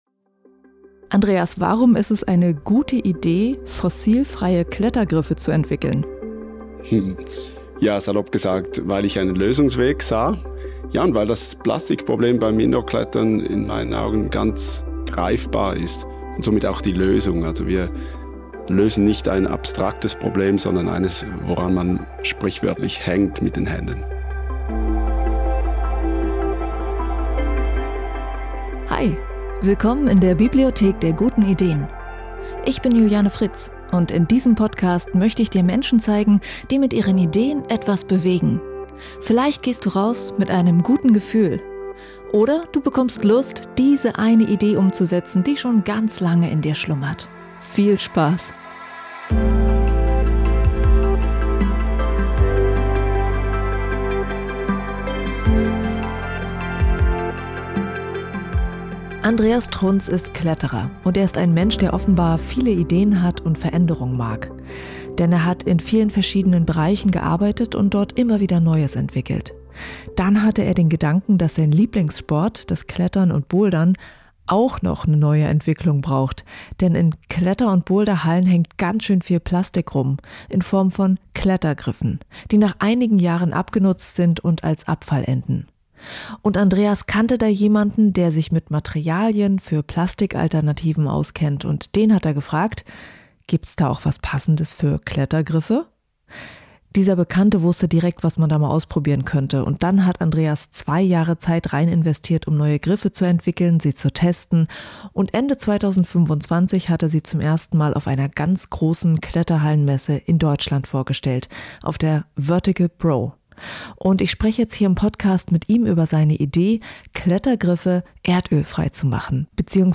Im Podcastinterview